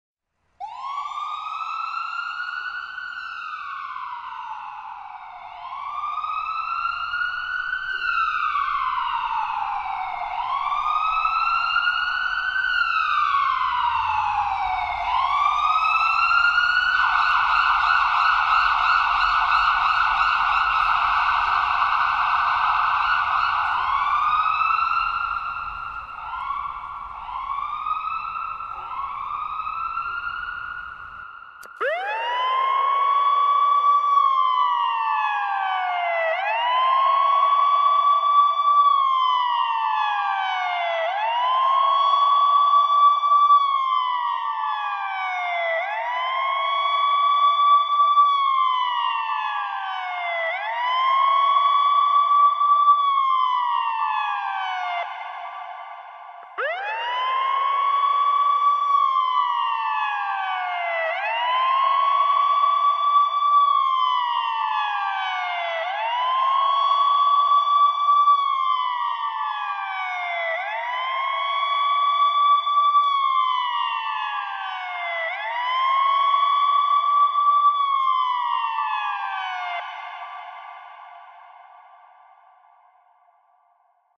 دانلود صدای آژیر آمبولانس 2 از ساعد نیوز با لینک مستقیم و کیفیت بالا
جلوه های صوتی